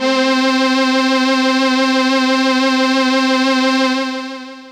55bd-syn11-c4.aif